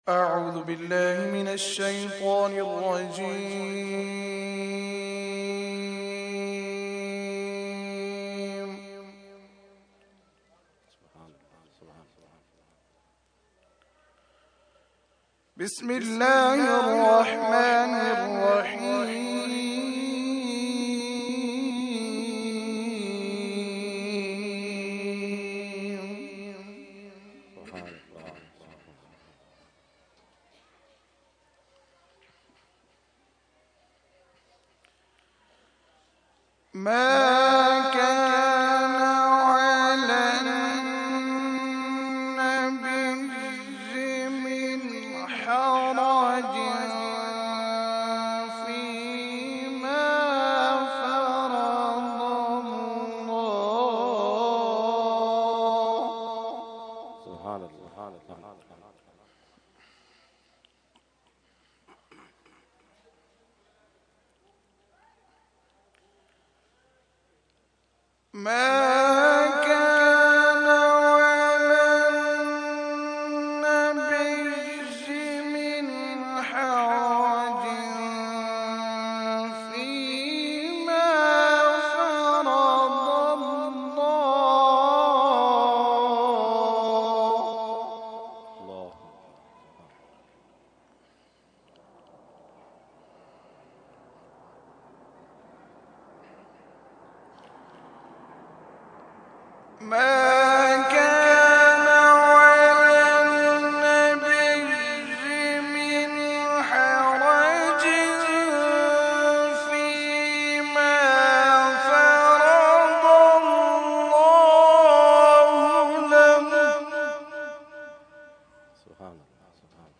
Qirat – Urs Qutbe Rabbani 2014 – Dargah Alia Ashrafia Karachi Pakistan